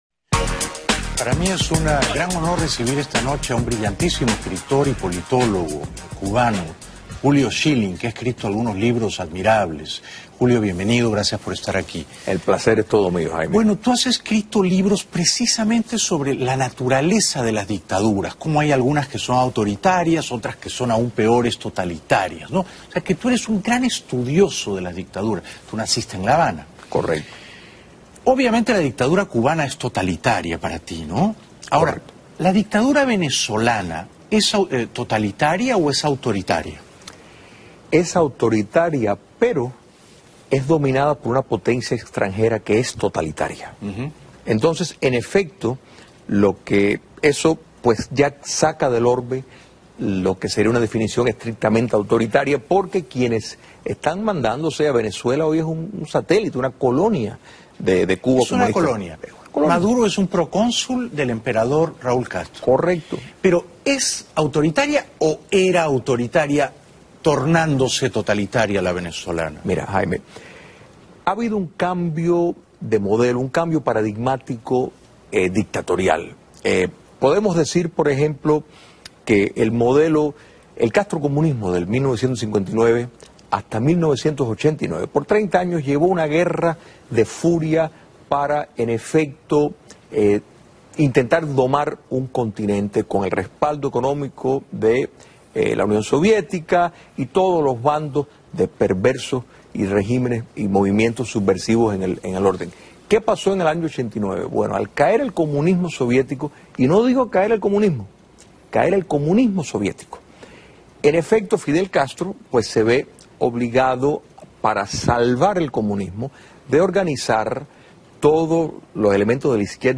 Audios de programas televisivos, radiales y conferencias